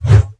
gnoll_mage_swish.wav